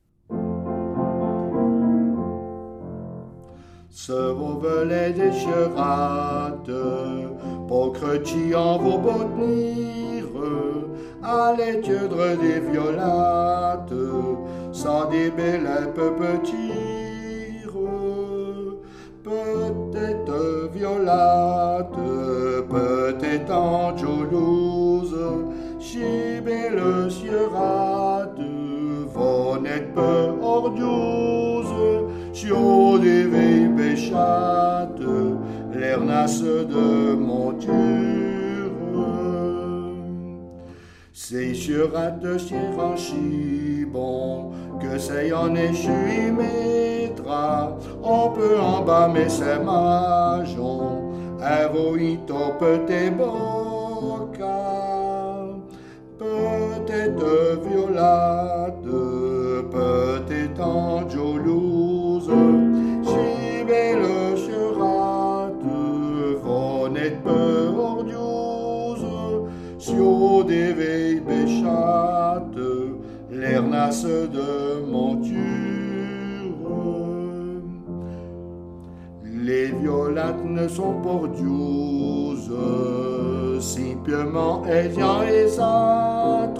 Le chant les Violettes